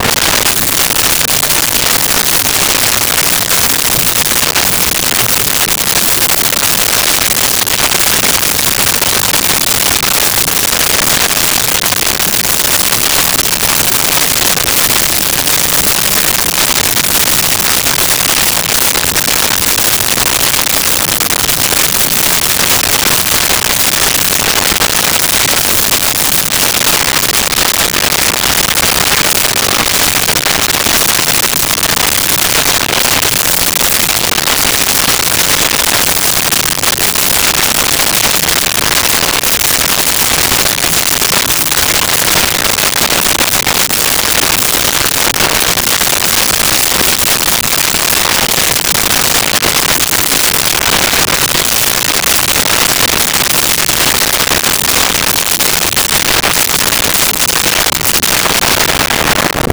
Underwater Bubbles Thick
Underwater Bubbles Thick.wav